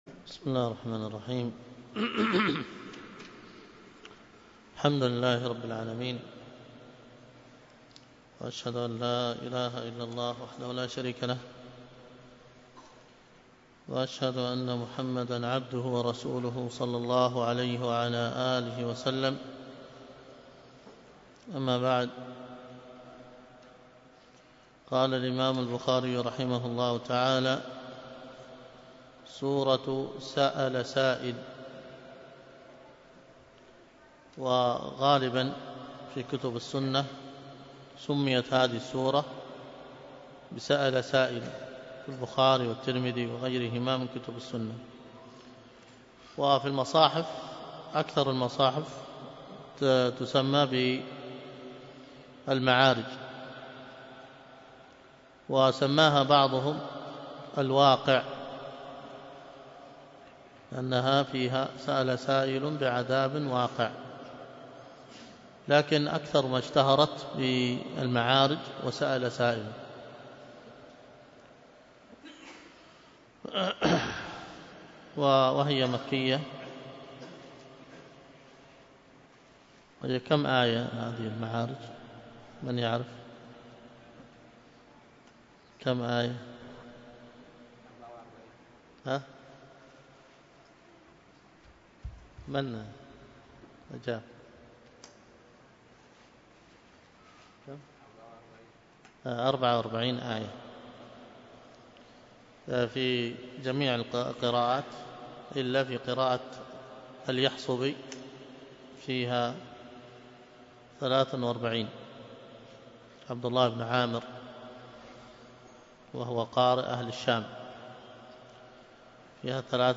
الدرس في كتاب التفسير من صحيح البخاري 254